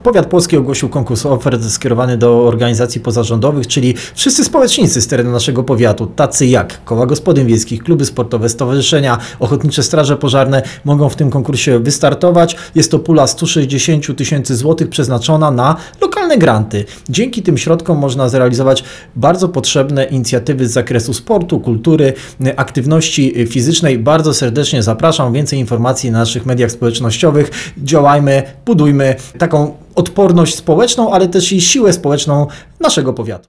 – przekazał Starosta Płocki Sylwester Ziemkiewicz.